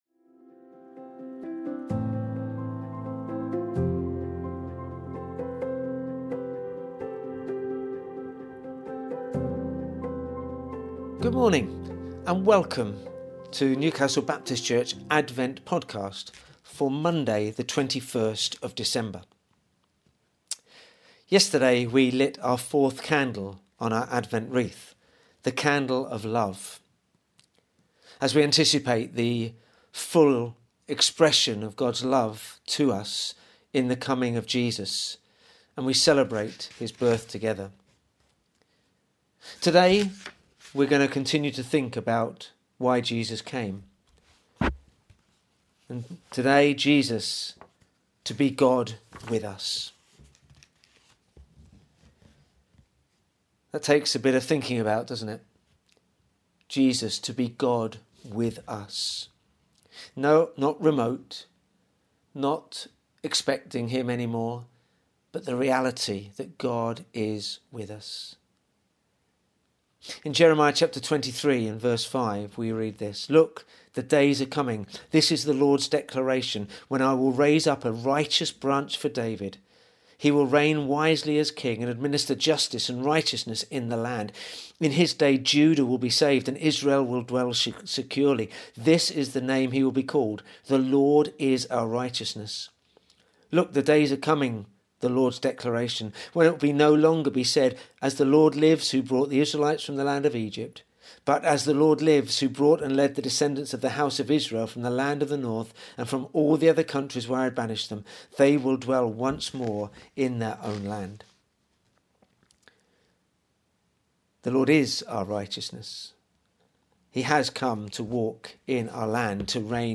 Welcome to our Advent devotionals for 2020. All this week we will be focussing on the fourth candle, the candle of love.